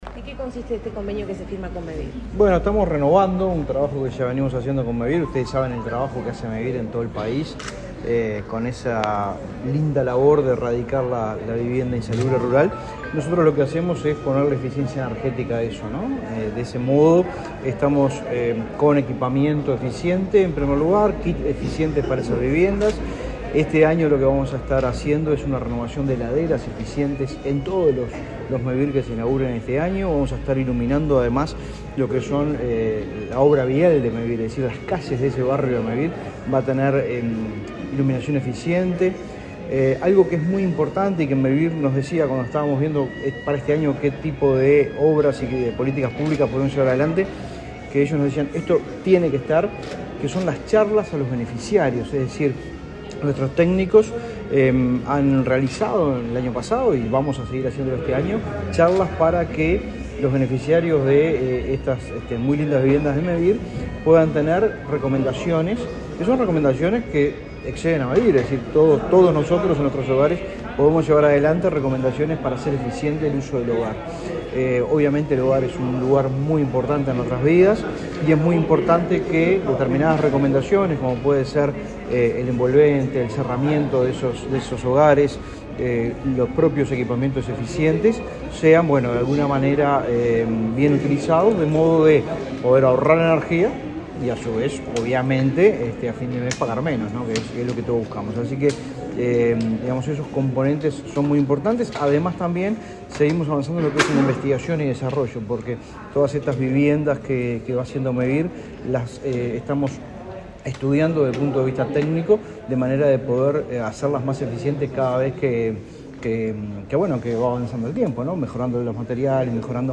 Declaraciones del director nacional de Energía, Fitzgerald Cantero
Declaraciones del director nacional de Energía, Fitzgerald Cantero 04/07/2023 Compartir Facebook X Copiar enlace WhatsApp LinkedIn El director nacional de Energía, Fitzgerald Cantero, dialogó con la prensa antes de participar en la firma de un acuerdo entre el Ministerio de Industria y Mevir.